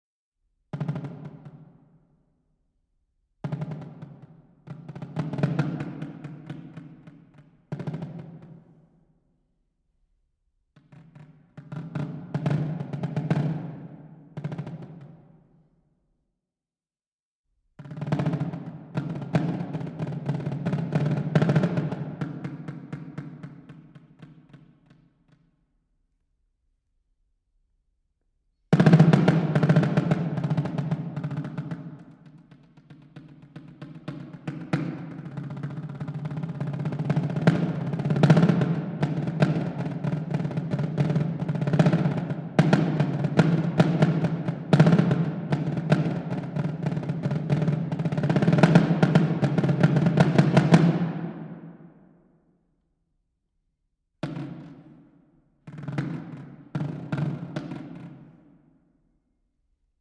Marimba
Vibraphone
Timpani